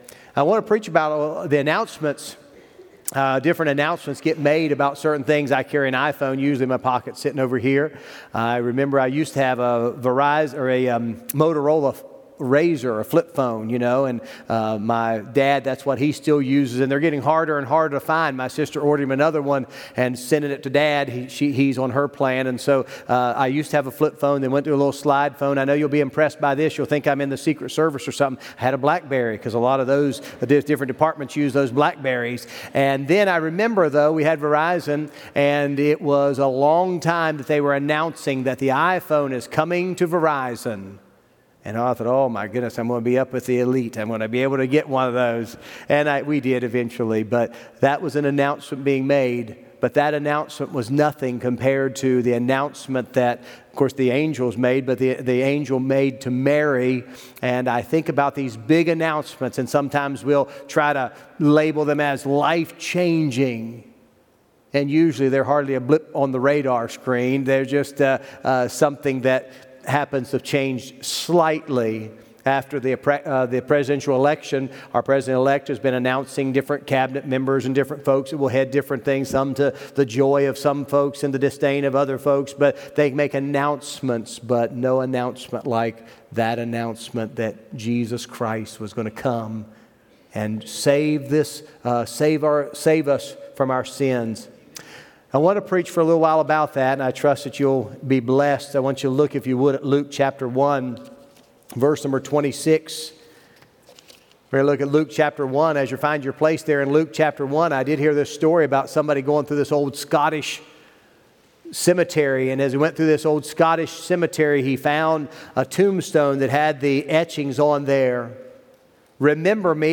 12-01-24 The Announcement BROADCAST | Buffalo Ridge Baptist Church